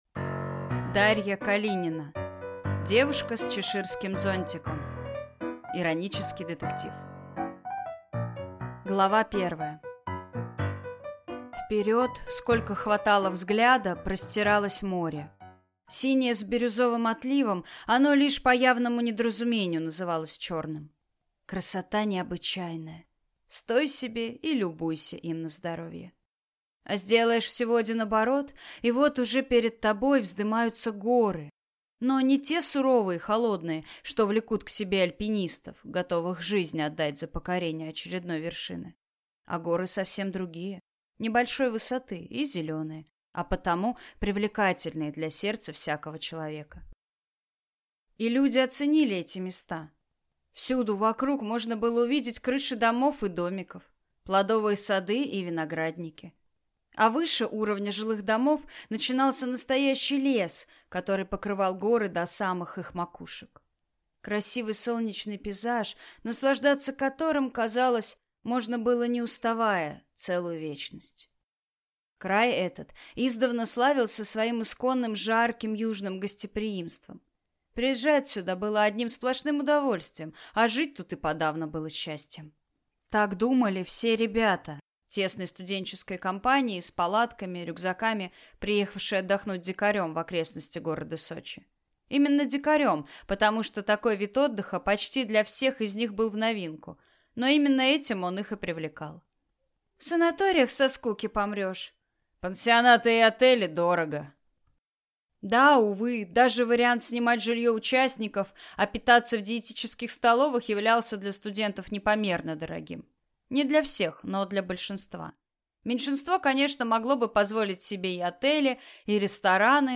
Аудиокнига Девушка с чеширским зонтиком | Библиотека аудиокниг